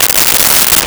Plastic Zipper Long
Plastic Zipper Long.wav